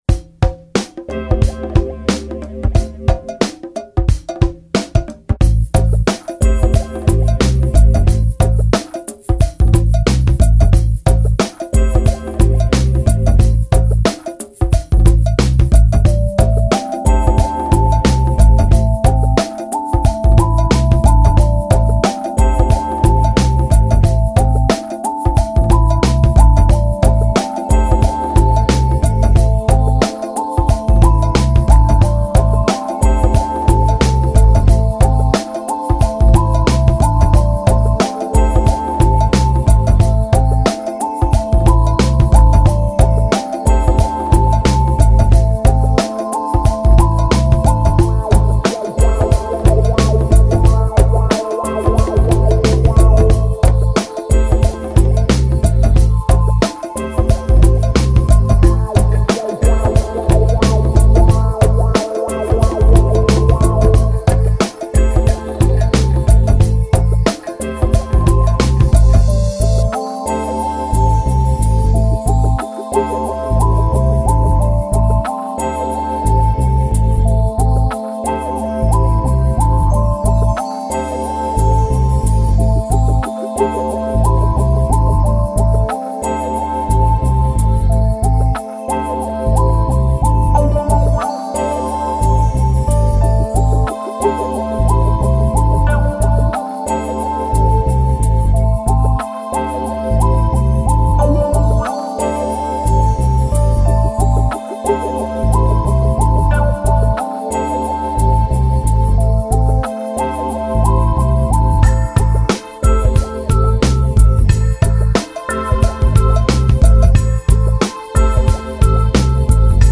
Download Chillout cc